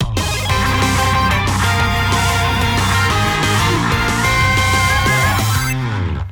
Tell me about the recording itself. Higher quality rip from the Wii U version.